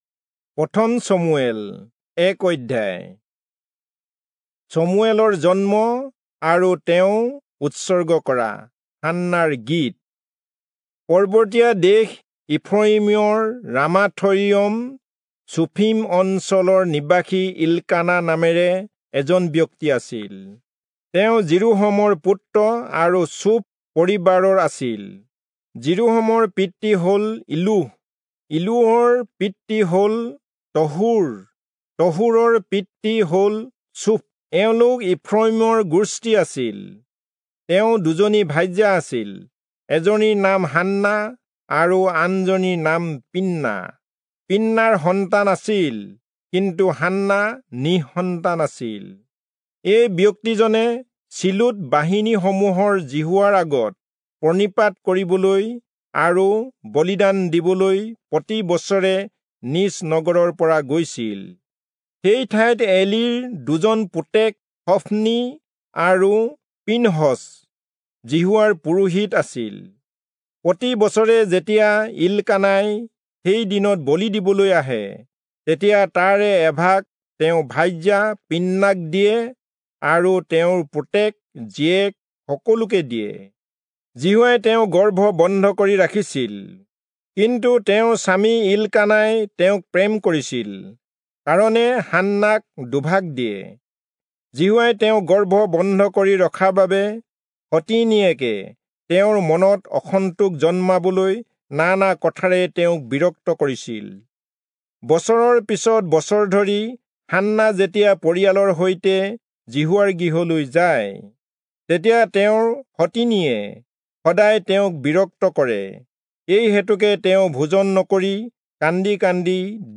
Assamese Audio Bible - 1-Samuel 14 in Nlt bible version